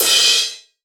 MG CRASH-2-S.WAV